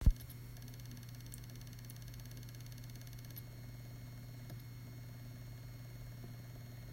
Neue WD Elements "klackert"
Direkt nach dem Anschließen ist mir aufgefallen, das sie ziemlich laut "klackt".
PS: Im Anhang eine Aufnahme vom "klackern" Anhänge Neue Aufnahme.mp3 Neue Aufnahme.mp3 59,8 KB